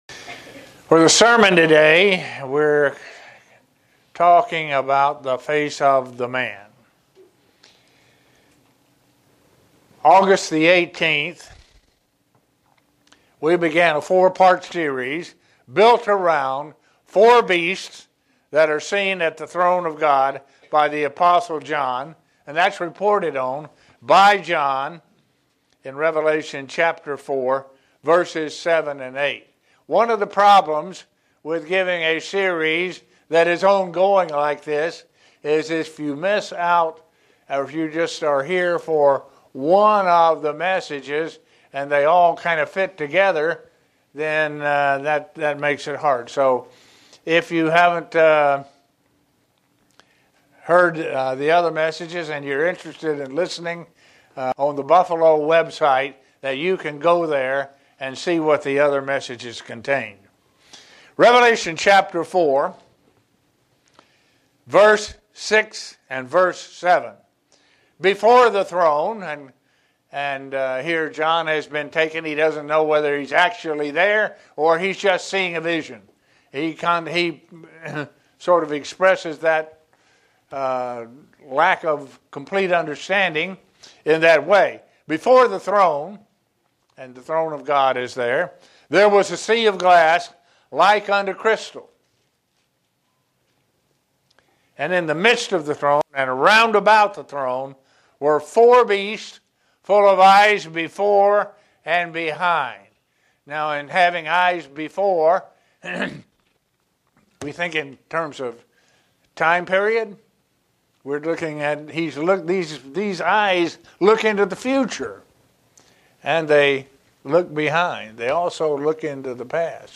Given in Buffalo, NY
Print How the Beast in Revelation 4:7 that has the Face of the Man represents Christ as depected in Luke. sermon Studying the bible?